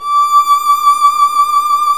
Index of /90_sSampleCDs/Roland L-CD702/VOL-1/STR_Violin 1 vb/STR_Vln1 Warm vb
STR VLN MT0R.wav